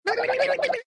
avatar_emotion_confused.ogg